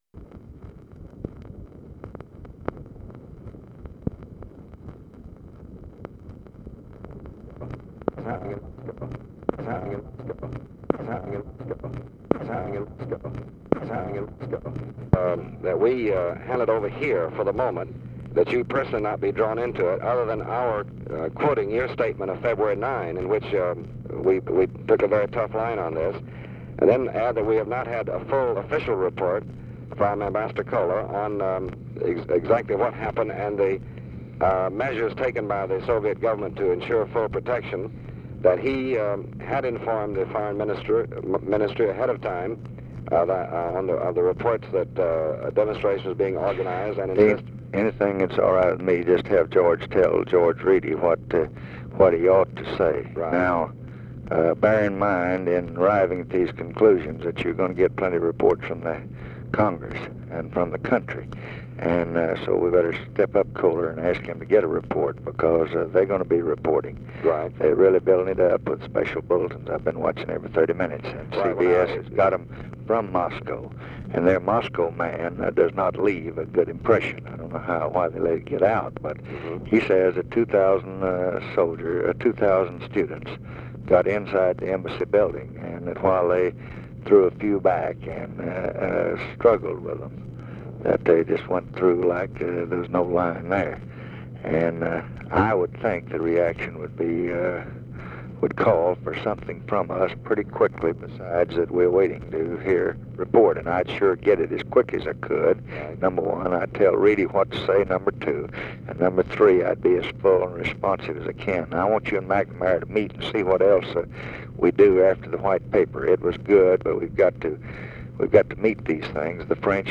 Conversation with DEAN RUSK, March 4, 1965
Secret White House Tapes